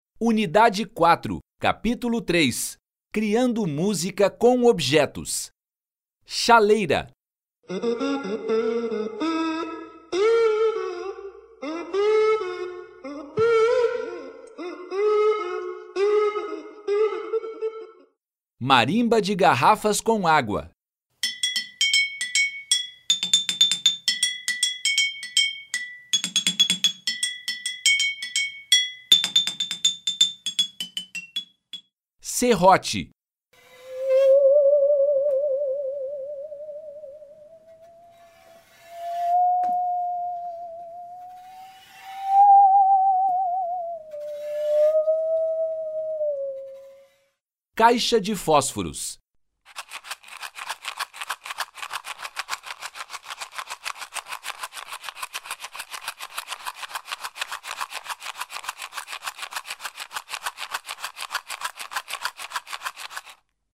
Criando música com objetos